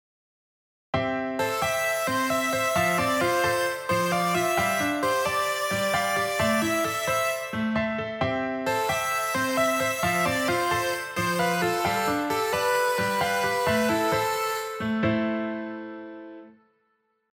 2：跳躍進行で始める
1音目と2音目の音程が大きく離れているパターンです。
サビのメロディーのサンプル2（跳躍進行）
上記のサンプルでは、1音目と2音目が半音7つ分離れています。